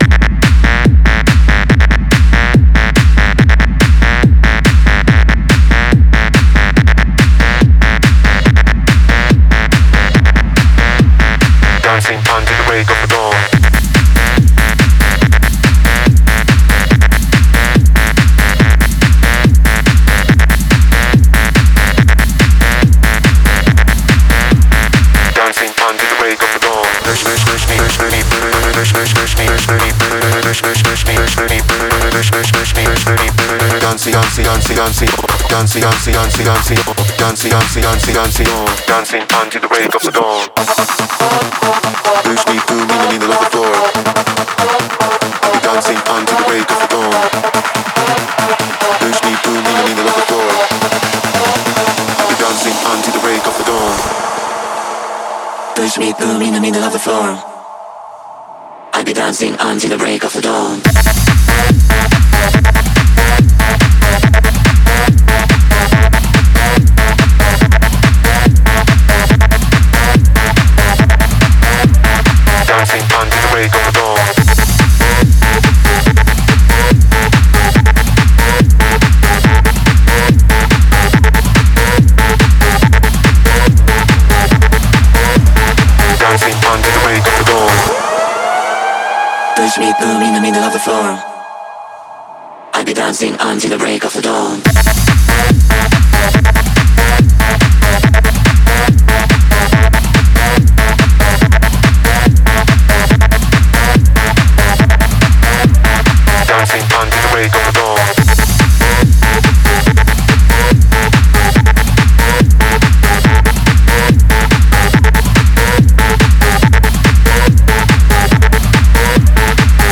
试听文件为低音质，下载后为无水印高音质文件 M币 11 超级会员 M币 6 购买下载 您当前未登录！